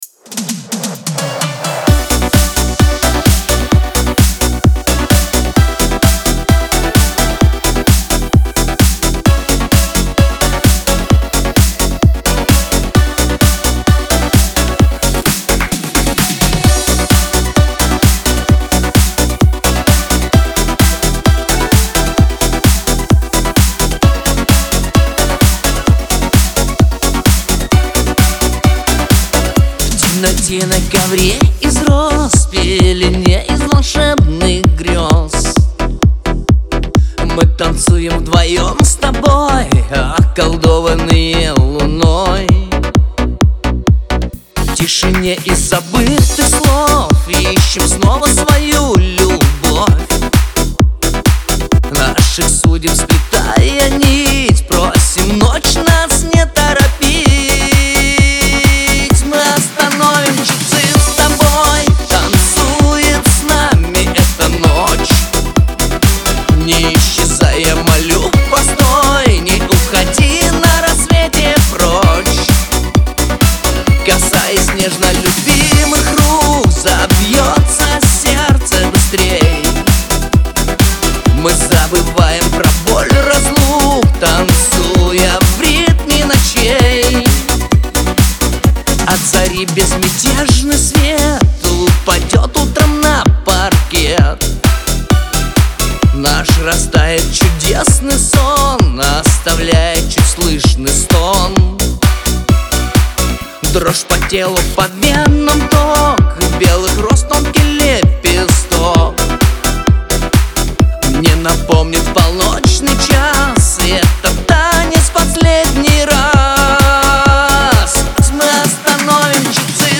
pop , dance